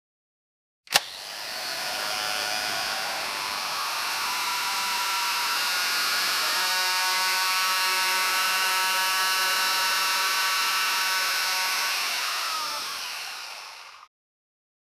electric-vehicle-pvtgtczu.wav